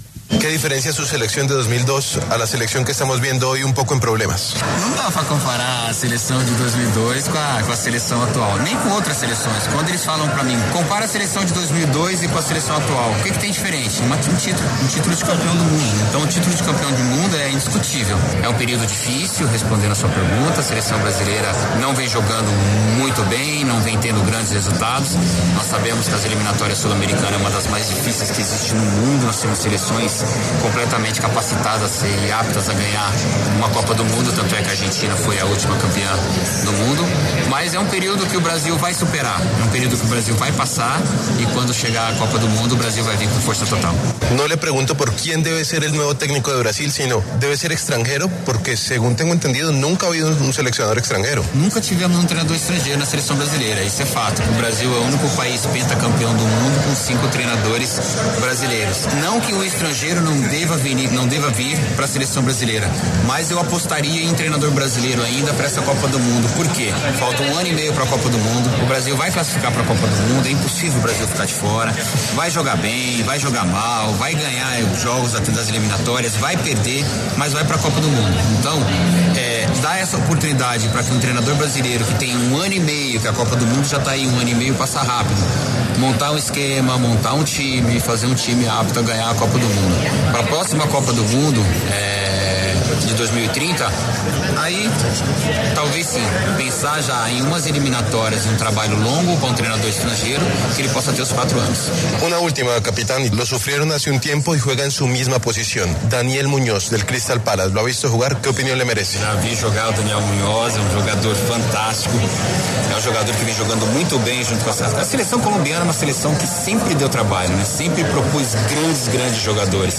Este lunes, 21 de abril, el capitán de la selección brasileña campeona del mundo en el 2002, Marcos Evangelista de Moraes (Cafú), estuvo en los micrófonos de W Radio, en donde habló sobre la actualidad de la selección brasileña y de Daniel Muñoz.